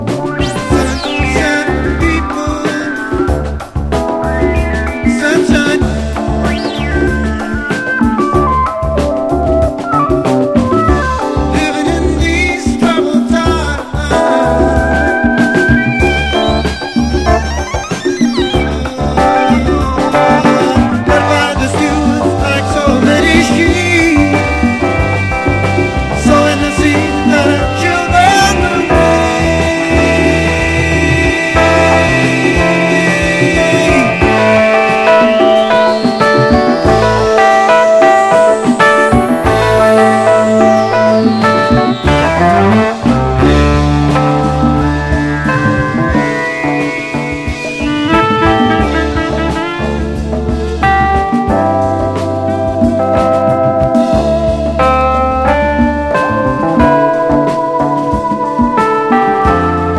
ROCK / 70'S
サイケデリックで幻想的に美しい